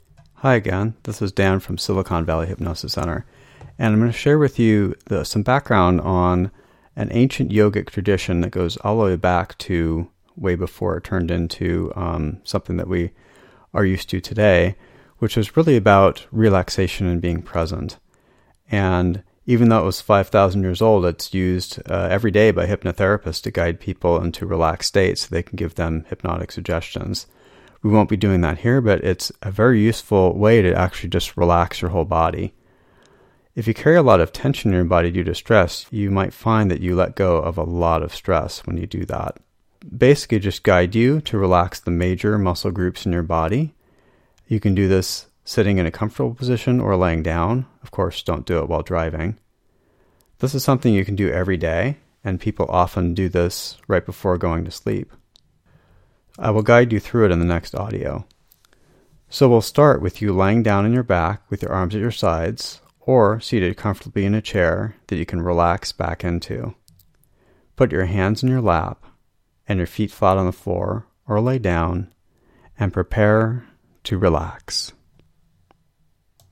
Bonus #2: Unadvertised bonus - Introduction and Guidance